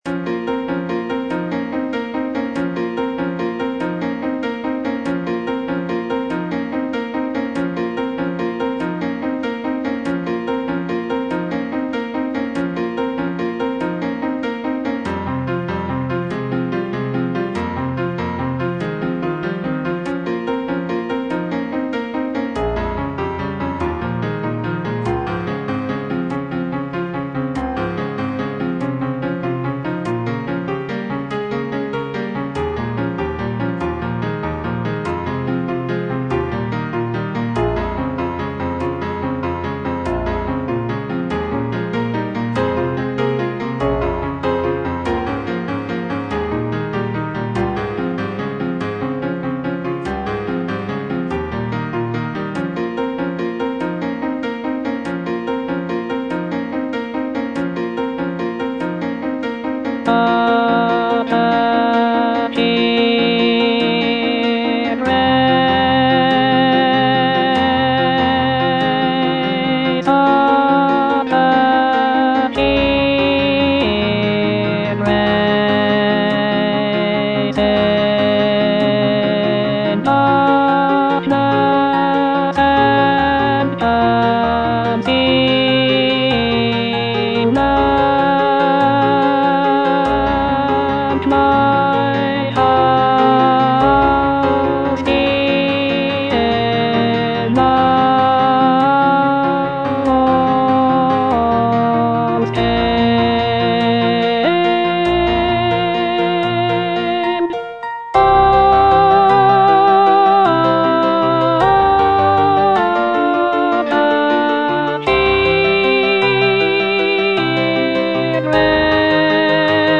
alto I) (Voice with metronome) Ads stop
choral work